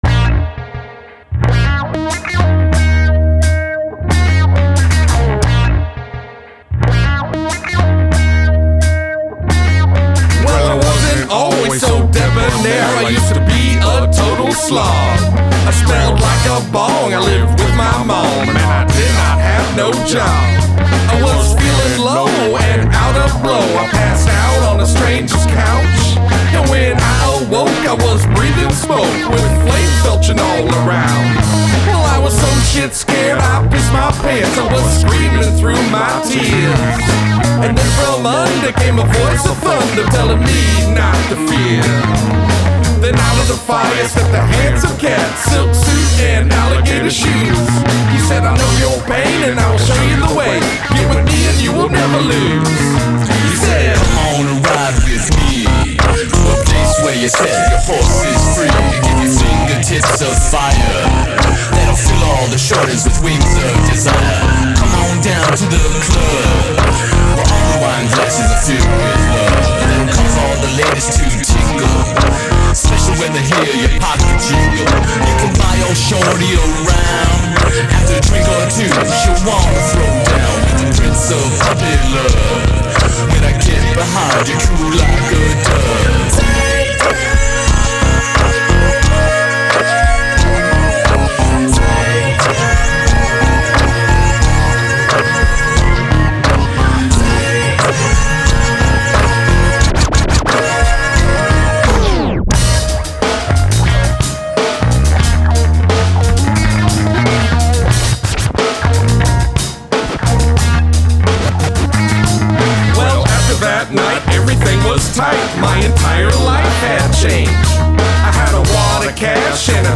Guest Rap
Nice retro vibe.